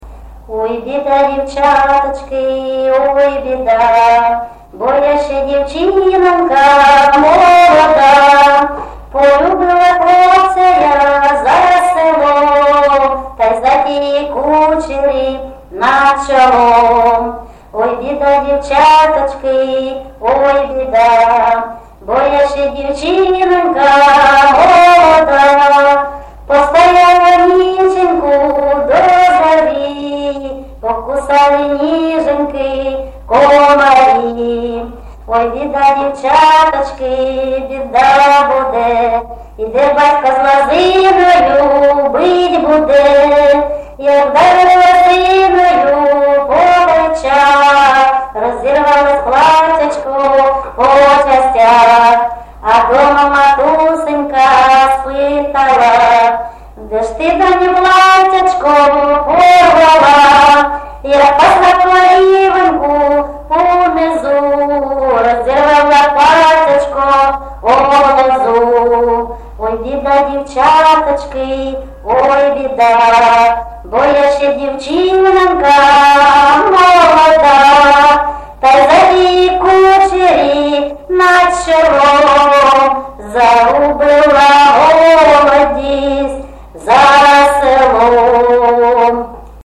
ЖанрПісні з особистого та родинного життя, Пісні літературного походження
Місце записус-ще Троїцьке, Сватівський район, Луганська обл., Україна, Слобожанщина